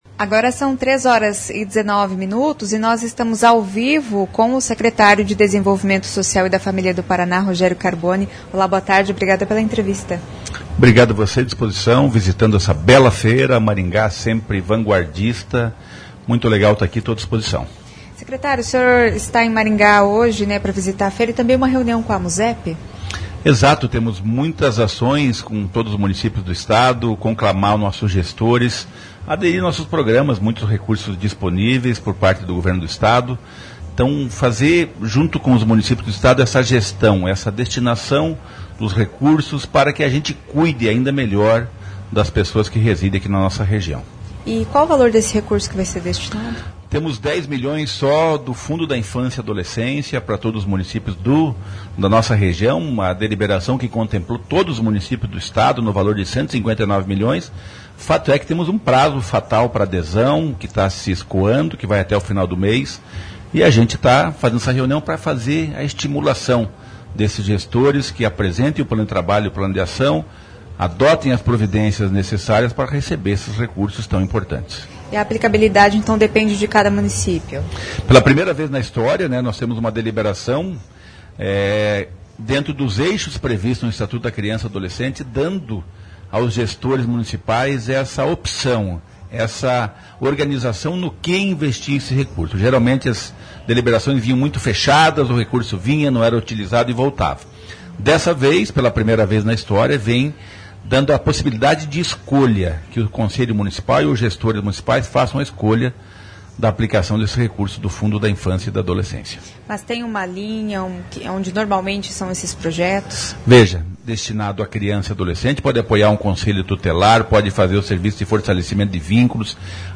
Rogério Carboni, secretário de desenvolvimento Social e Família. CBN Maringá.
De acordo com o secretário, pela primeira vez a aplicabilidade desse recurso está mais livre para os gestores, desde que atendam aos critérios do Estatuto da Criança e do Adolescente. Ouça a entrevista.